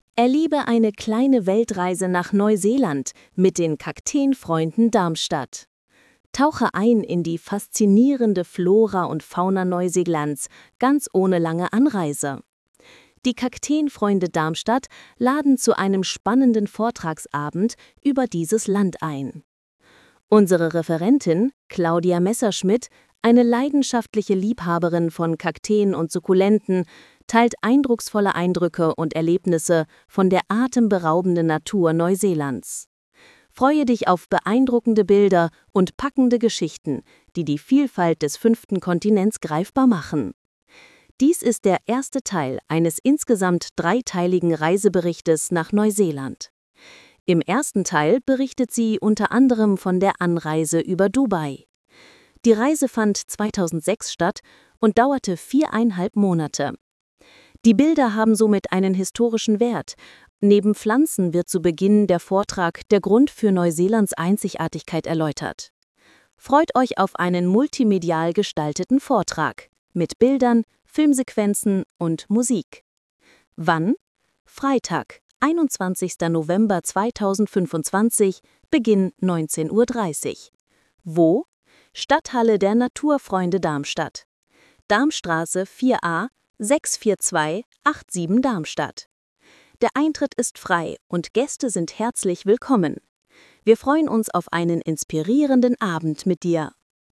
Für mehr Barierefreiheit den Text hier vorlesen lassen: